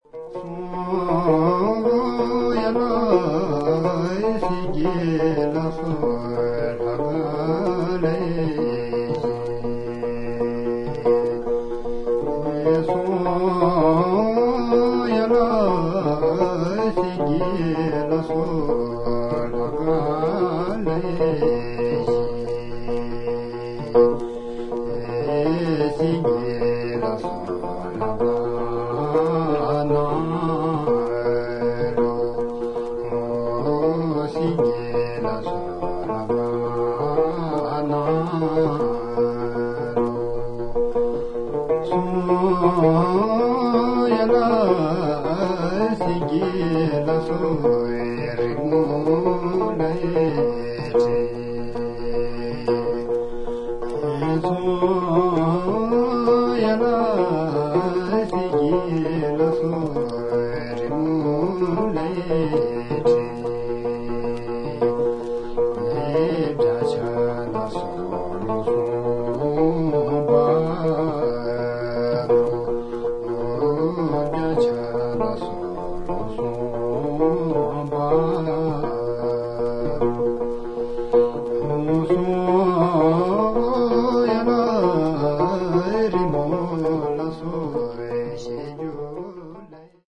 MEDIA : VG＋ ※※A面1曲目〜4曲名にかけて薄いスリキズあり。
A1 Song With Lute Self-Accompagnement